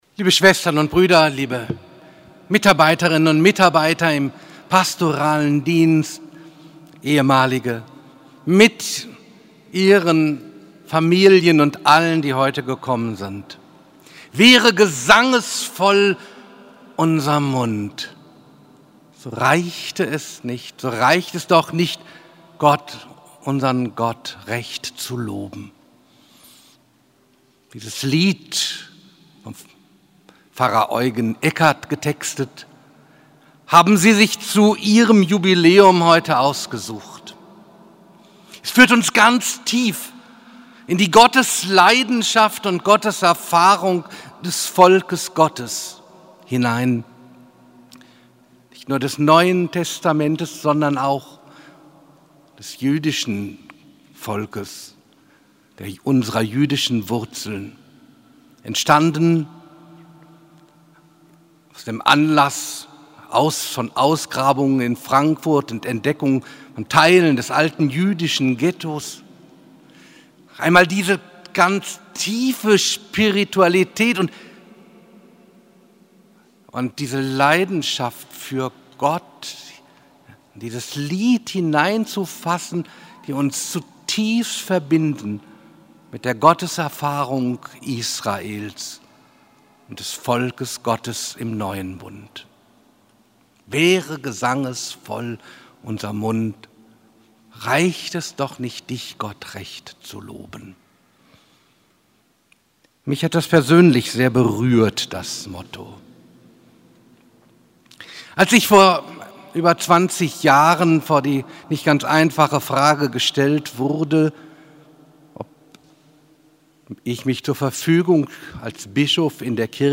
05.04.2026 Predigtmitschnitt zum Pontifikalamt Ostersonntag 2026